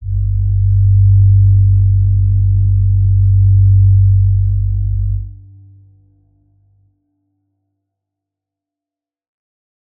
G_Crystal-G2-mf.wav